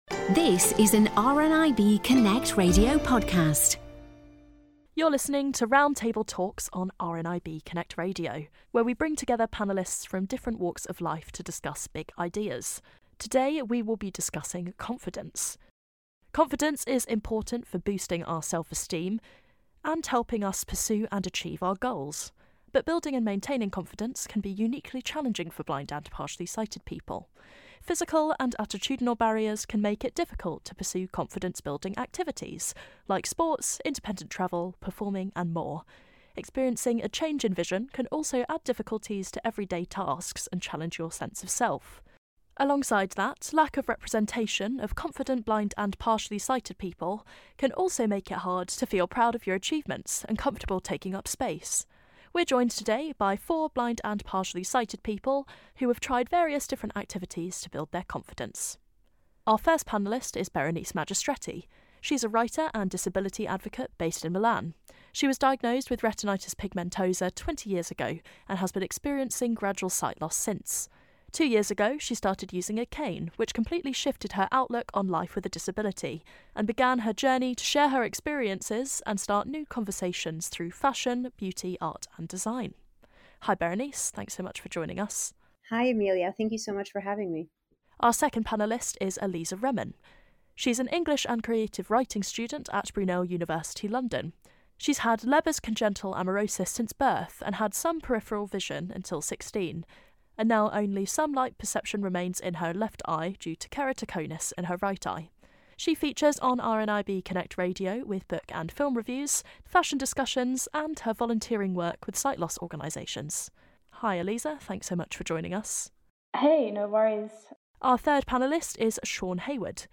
We gathered four blind and sighted panellists to talk it out for our latest Roundtable.